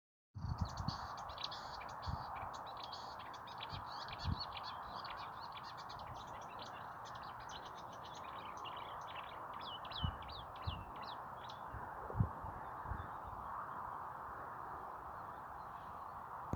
Птицы -> Славковые -> 1
болотная камышевка, Acrocephalus palustris
СтатусПоёт